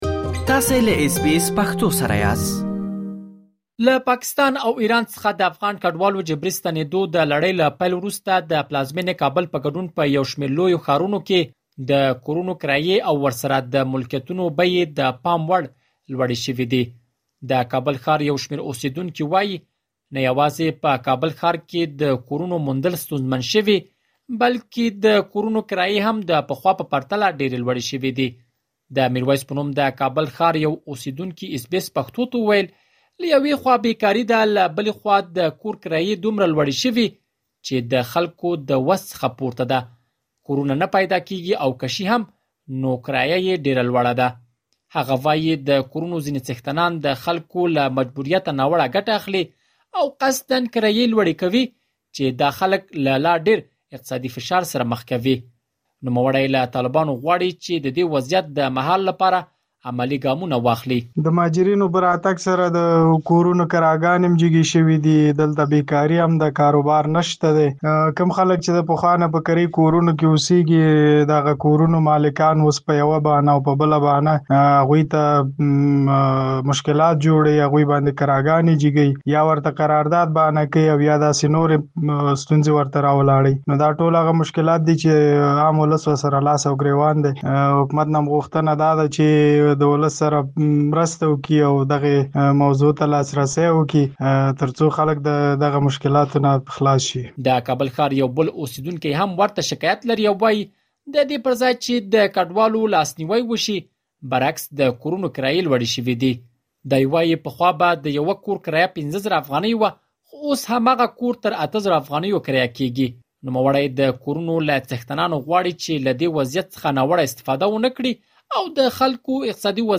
له پاکستان او ایران څخه د افغان کډوالو د جبري ستنېدو د لړۍ له پیل وروسته د پلازمینې کابل په ګډون په یو شمېر لویو ښارونو کې د کورونو کرايي او ورسره د ملکیتونو بیې د پام وړ لوړې شوې دي. مهربان وکړئ لا ډېر معلومات په رپوټ کې واورئ.